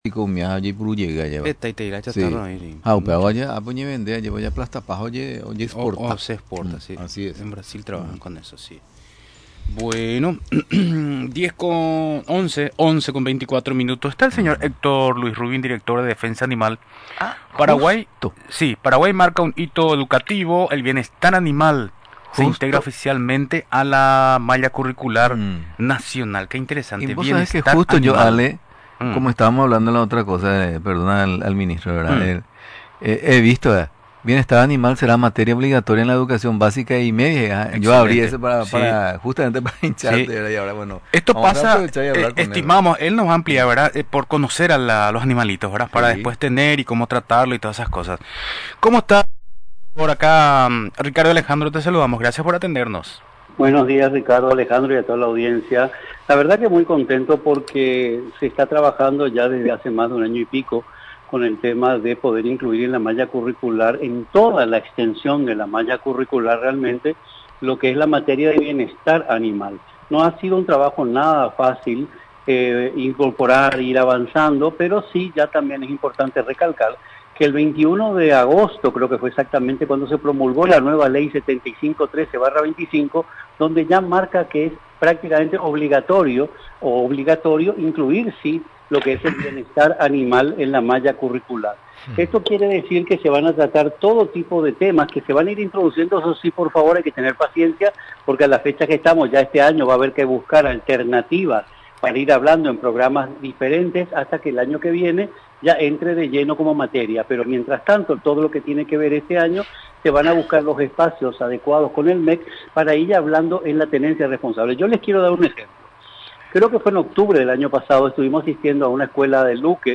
A partir de la promulgación de la Ley N.º 7513/25, el sistema educativo paraguayo asume un compromiso histórico con la ética y la sensibilidad social, explicó el director de Defensa Animal, Héctor Luis Rubín.